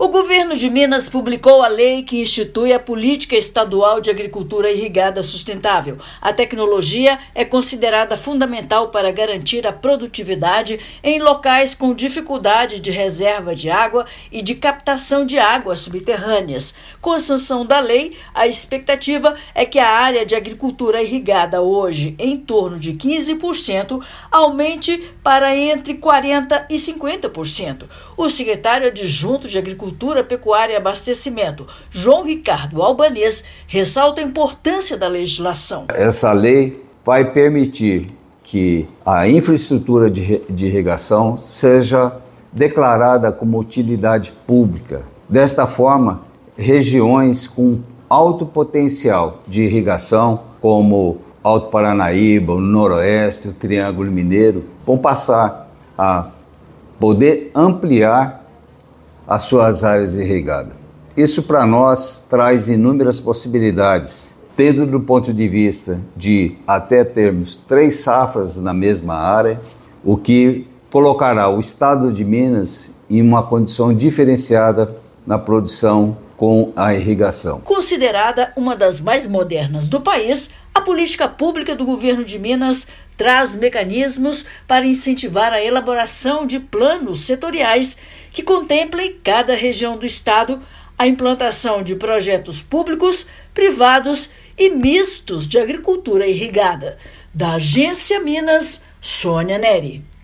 [RÁDIO] Governo de Minas sanciona lei que vai permitir aumento das áreas irrigadas
Com a política pública, área irrigada no estado deve subir dos atuais 15% para cerca de 50%. Ouça matéria de rádio.